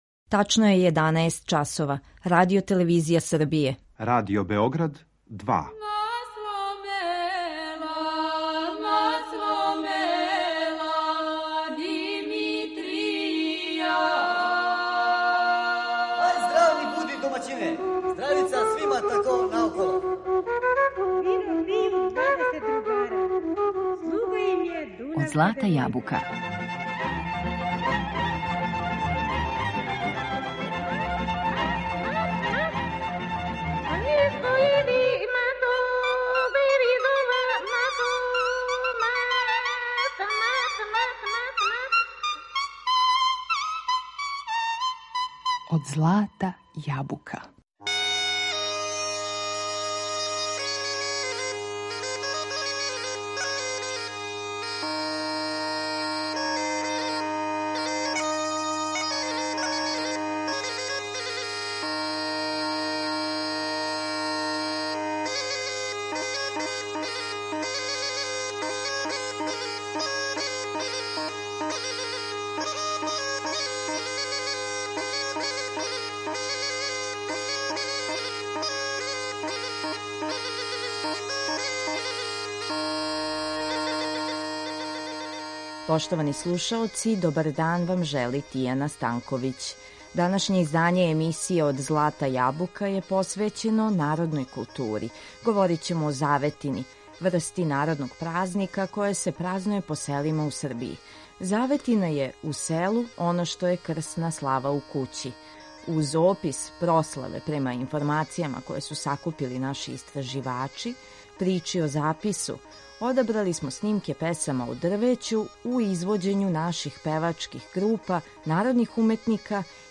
Говорићемо о заветини, врсти народног празника који се празнује по селима у Србији. Захваљујући нашим истраживачима, чућемо како изгледају те прославе, затим причу о запису ‒ светом дрвету, а одабрали смо пригодне снимке песама у извођењу певачких група и народних уметника песме и свирке.